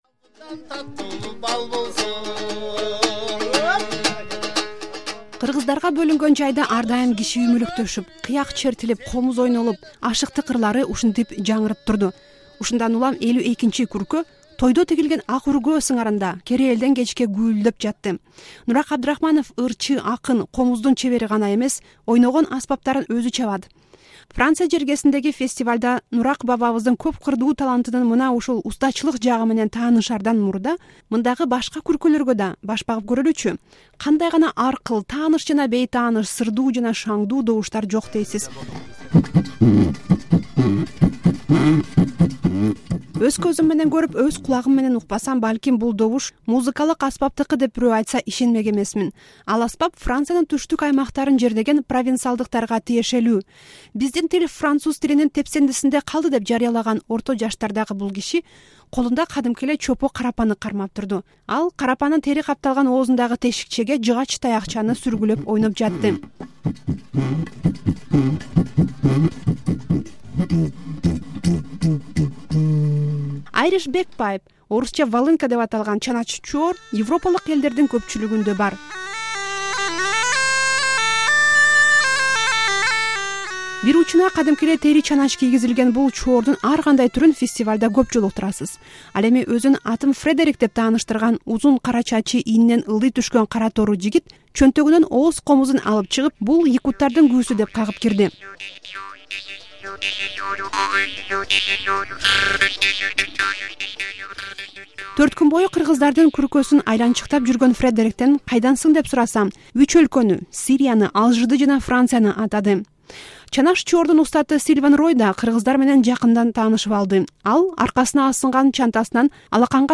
Дүйнөнүн көп өлкөлөрүн кыдырган атактуу комузчу Нурак Абдрахманов 2005-жылы Франциянын Сан-Шартьер фестивалына катышкан эле. Сан-Шартьер айылында жыл сайын көөнө музыканын жармаңкеси болуп турат.